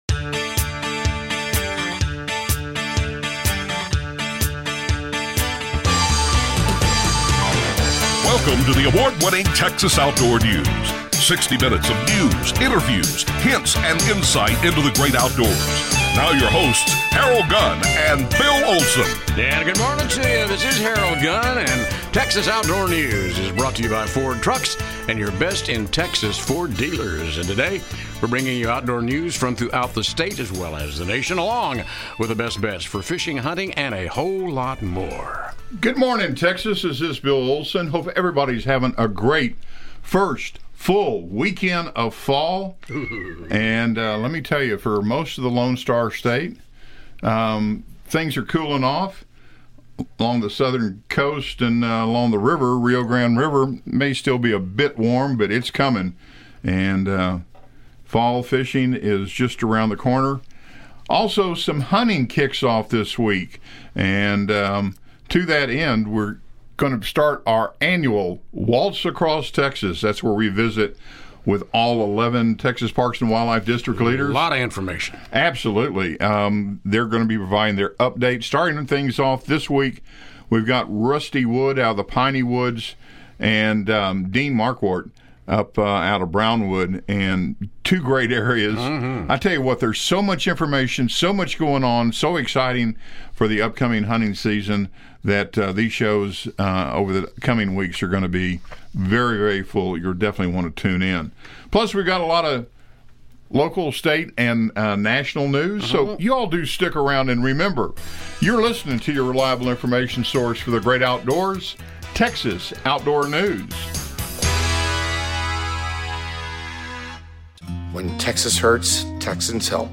Out annual "Waltz Across Texas" featuring all Texas Parks and Wildlife Department District Leaders reporting on current game animal and habitat conditions.